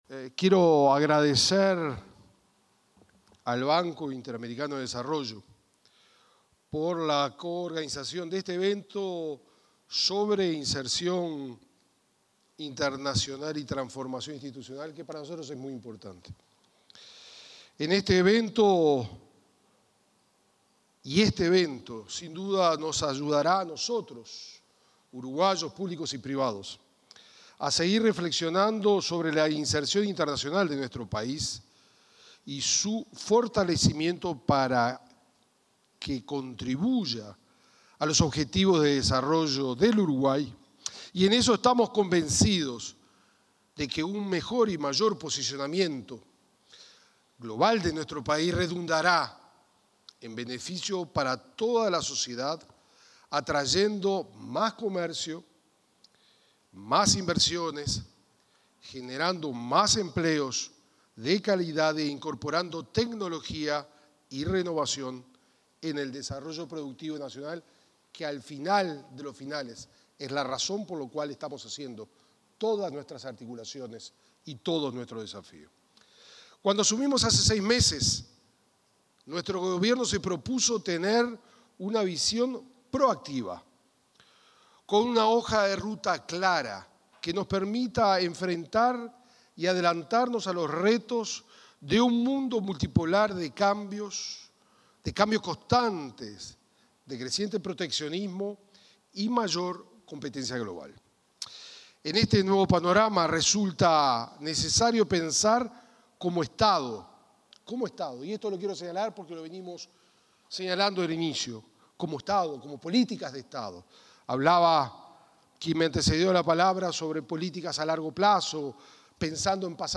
Palabras del ministro de Relaciones Exteriores, Mario Lubetkin
En oportunidad de la apertura del encuentro Uruguay y la Organización para la Cooperación y el Desarrollo Económicos: Diálogo Estratégico sobre